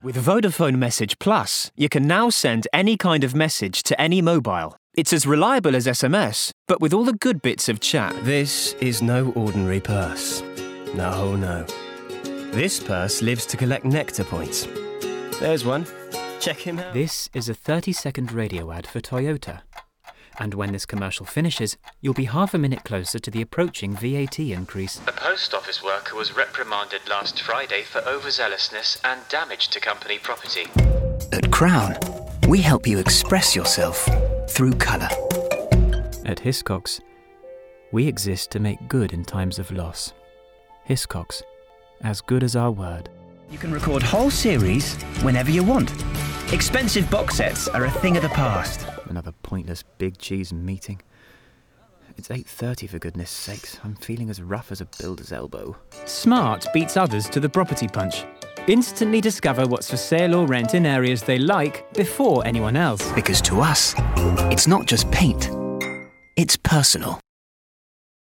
Very versatile with a huge range of accents.
• Male
• Standard English R P